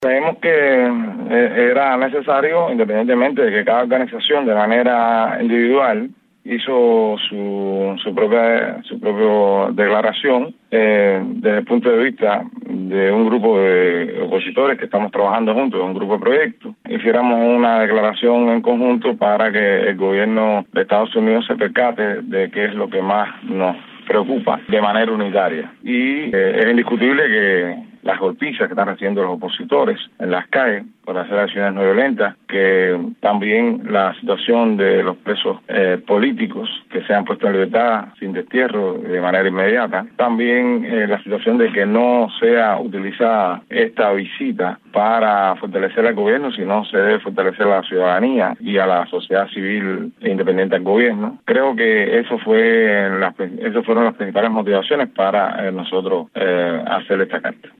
Guillermo Fariñas, uno de los firmantes, explica las razones que llevaron a emitir este manifiesto a favor de la visita de Obama.